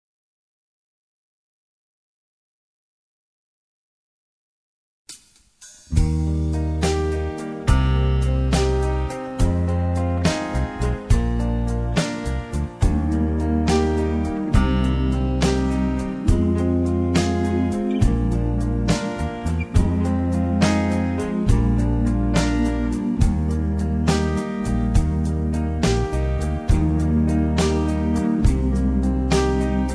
karaoke , sound tracks , backing tracks